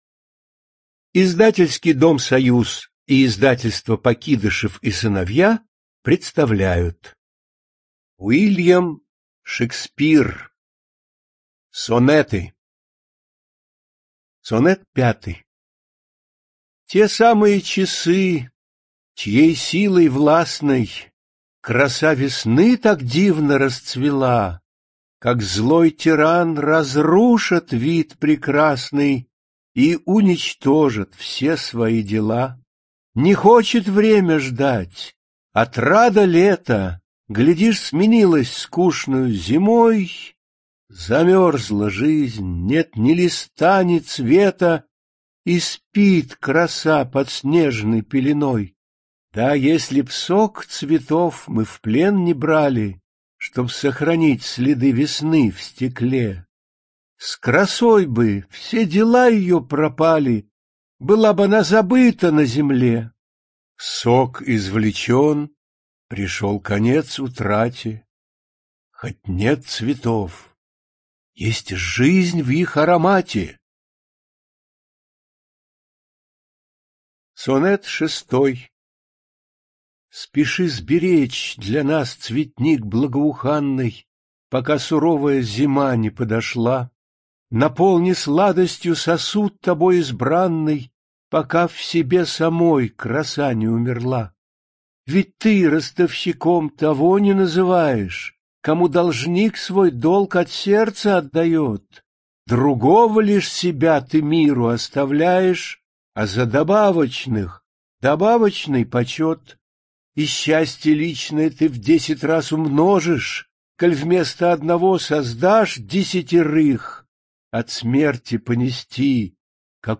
ЧитаетСмехов В.
ЖанрПоэзия
Студия звукозаписиСоюз